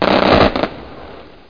CRACKER.mp3